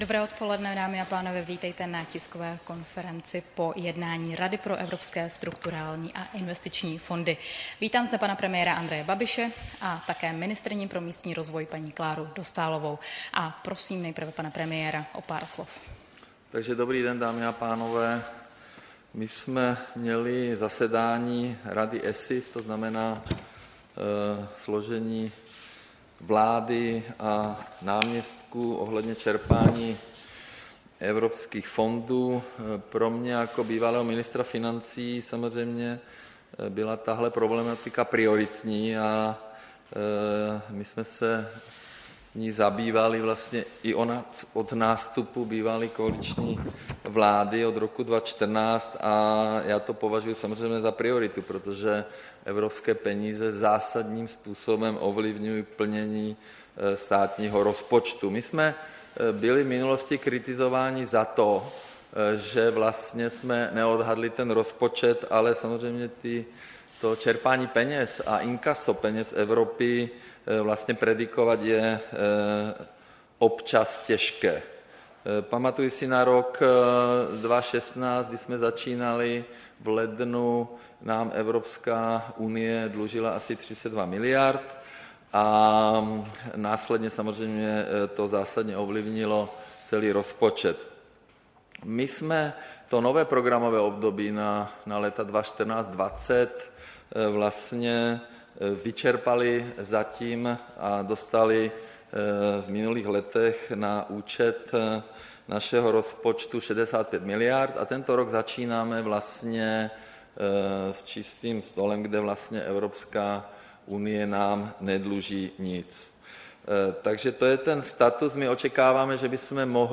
Tisková konference po jednání Rady pro Evropské strukturální a investiční fondy, 11. ledna 2018